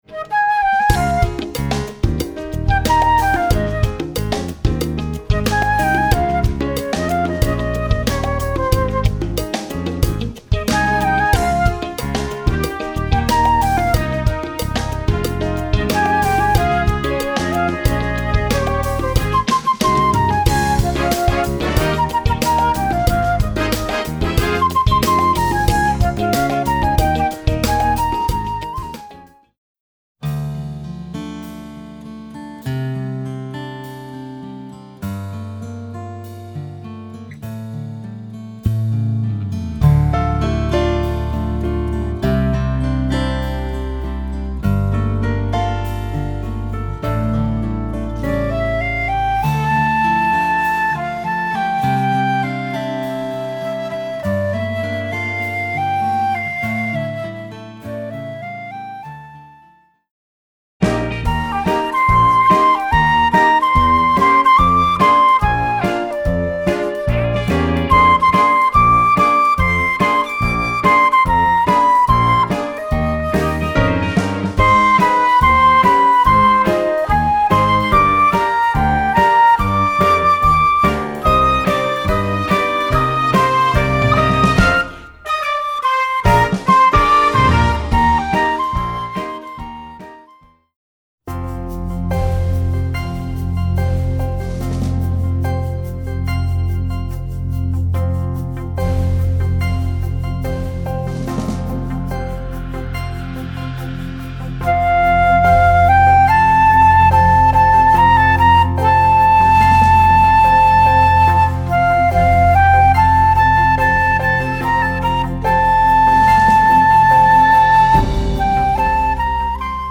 Voicing: Flute Collection